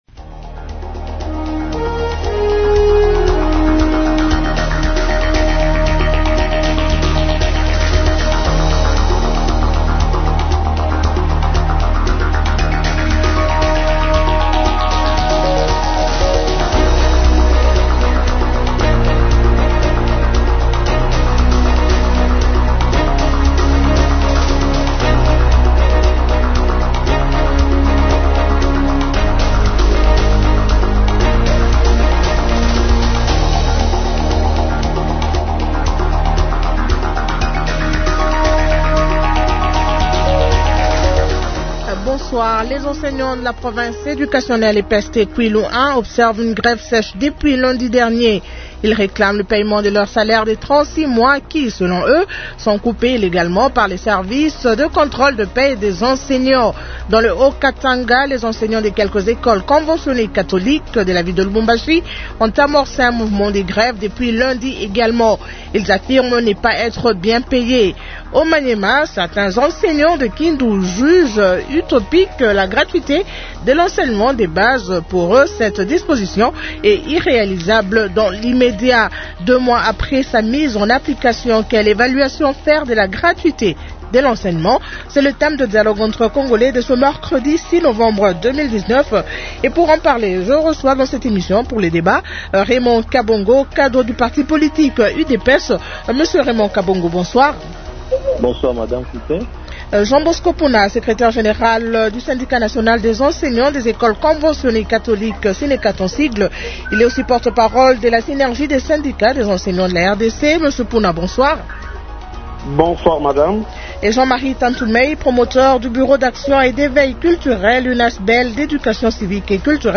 Développement de l'actualité politique